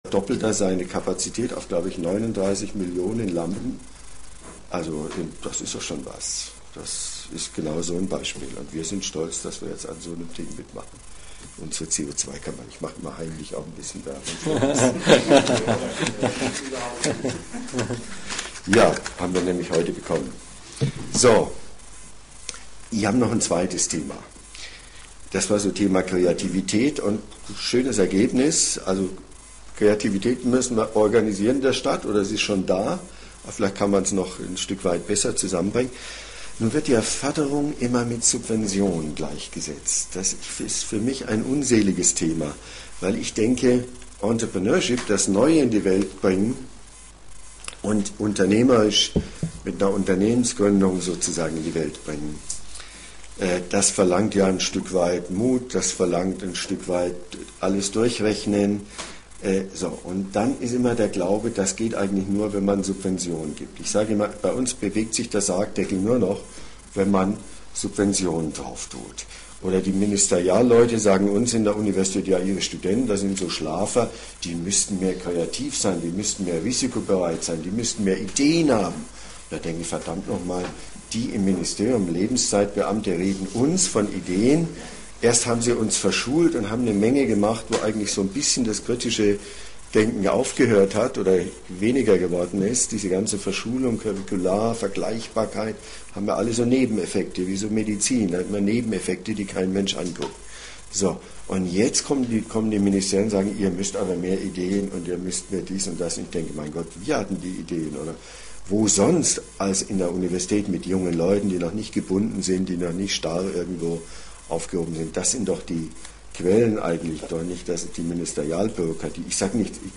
Labor-Interview